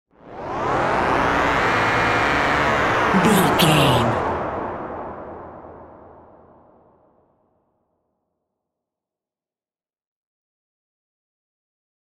Low Brain Stinger.
Atonal
scary
ominous
dark
suspense
eerie
synth
ambience
pads
eletronic